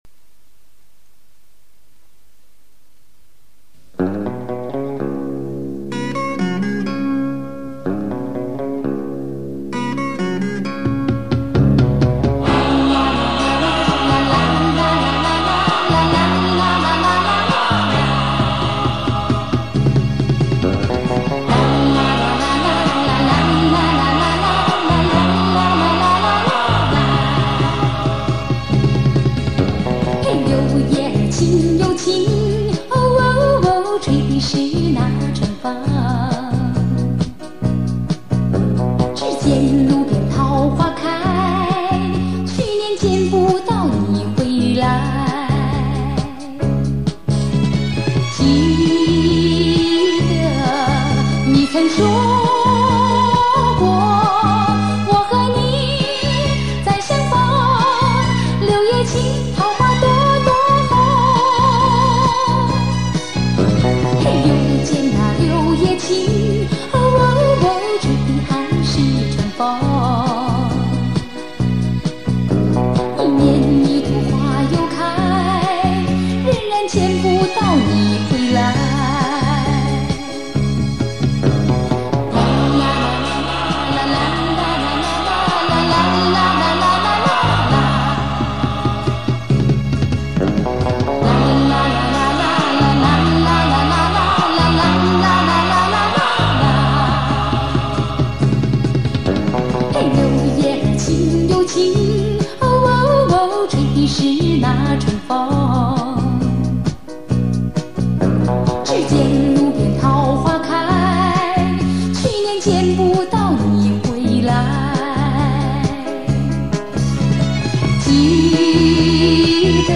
难得，老的卡带版本，跟进收下啦。
老音乐，旋律优美、唱的字正腔圆，我喜欢！